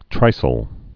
(trīsəl, -sāl)